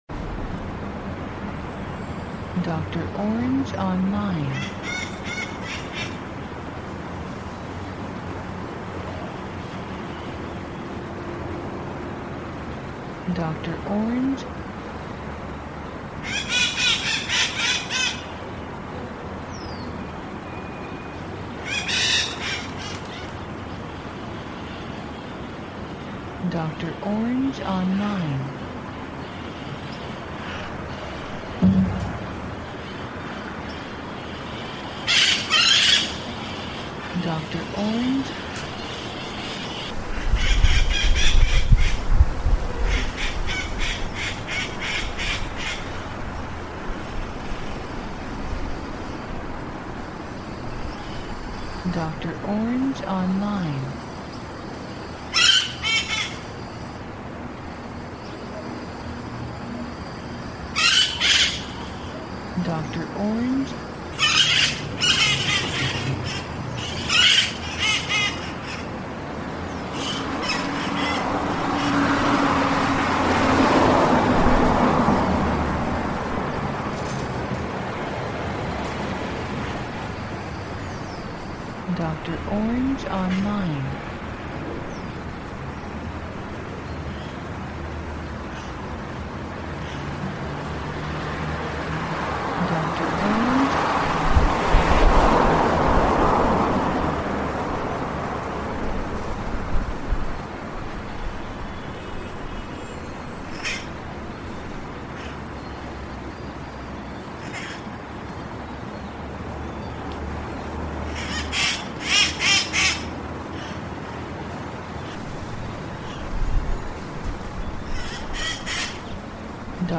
Ambiente Ambisónico Exterior Calle, Papagayos, Automóviles pasan
Archivo de audio AMBISONICO, 96Khz – 24 Bits, WAV.